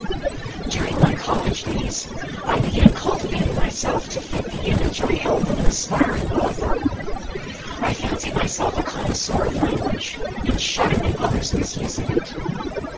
Table 2: Several examples of speech projected onto subsets of cepstral coefficients, with varying levels of noise added in the orthogonal dimensions.